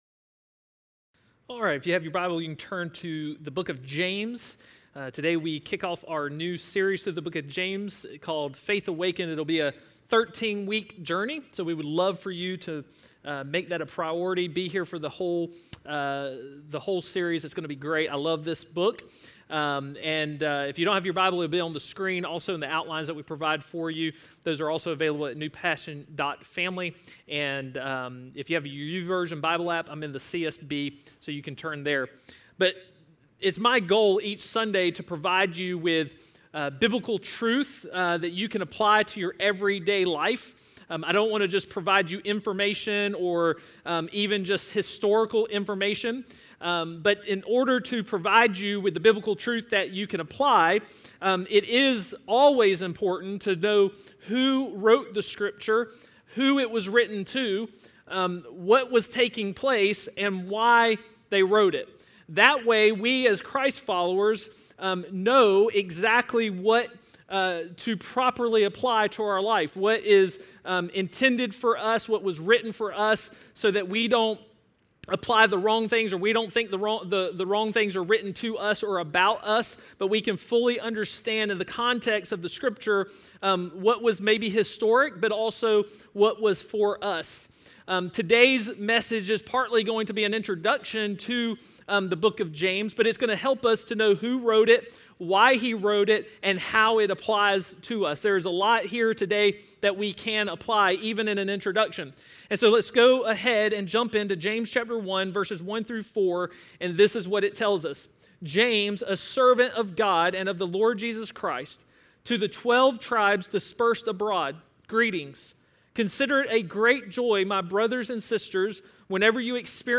Sermons – Hope Church Augusta